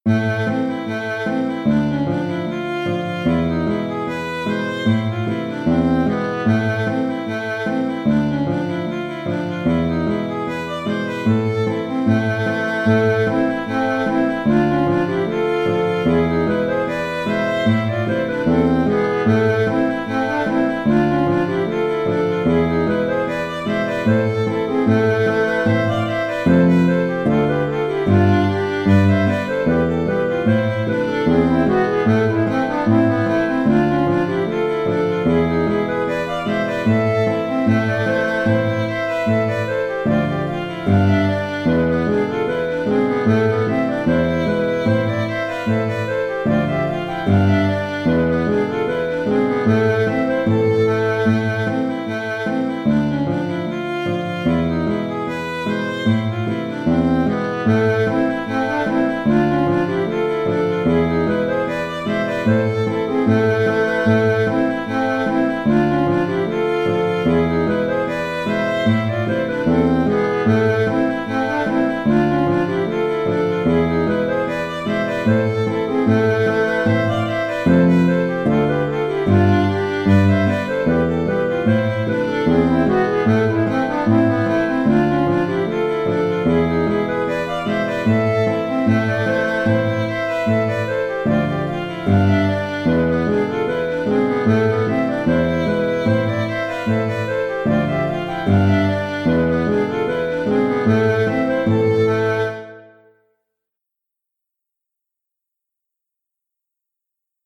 Scottish finlandaise "Pour sortir" (Scottish) - Musique folk
C'est pendant le premier confinement de 2020 que j'ai travaillé le contrechant, que j'ai voulu un peu élaboré.